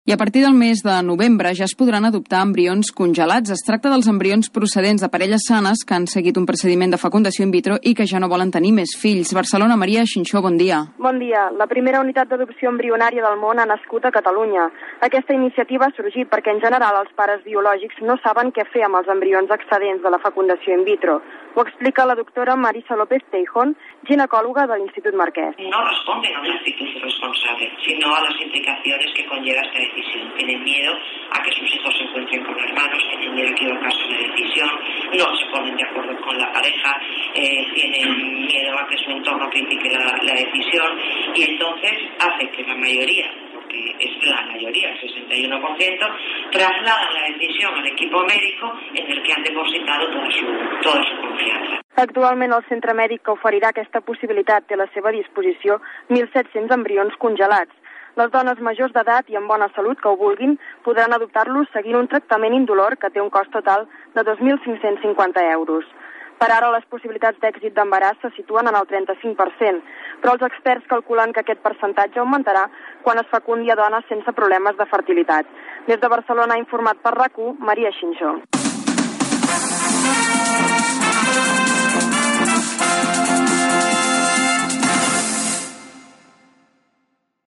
Informatiu
FM